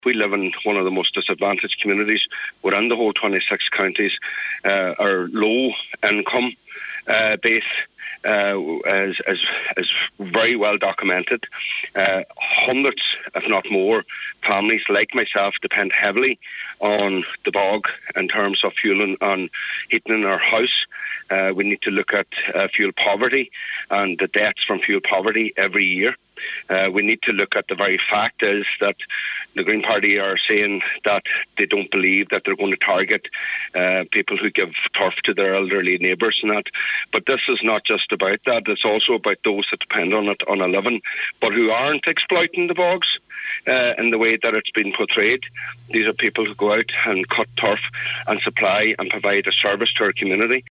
But Local Cllr Michael Cholm Mac Giolla Easbuig disagrees and says the issue runs much deeper: